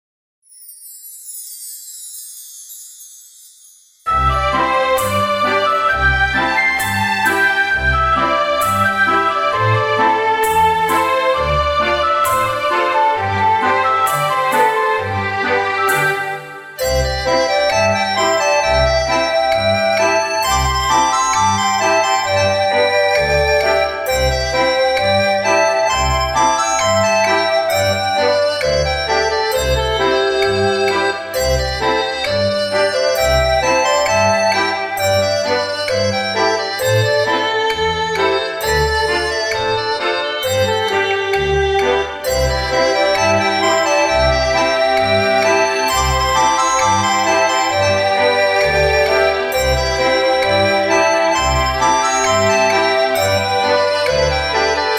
伴奏：茉莉花（原调慢速）